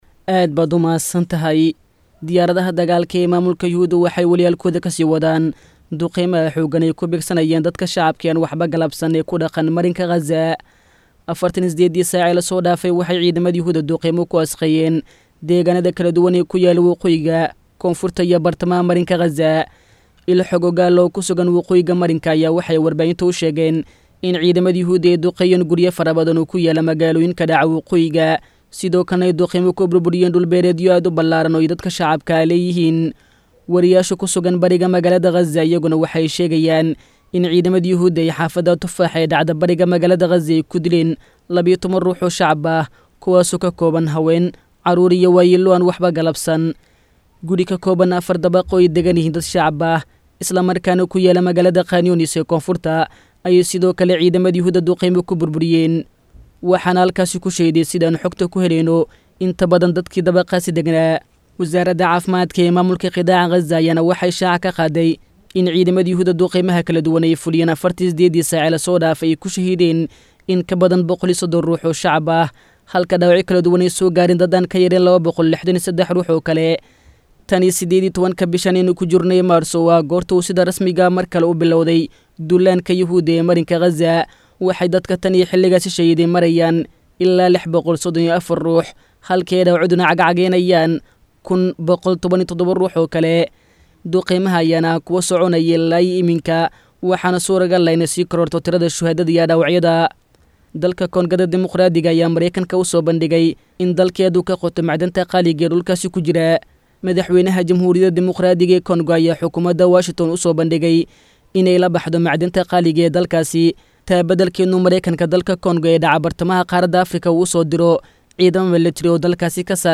Xubinta Wararka Caalamka oo ku baxda Barnaamijka Dhuuxa Wareysiyada ee idaacadda Islaamiga ah ee Al-Furqaan, waxaa lagu soo gudbiyaa wararkii ugu dambeeyay ee daafaha Caalamka.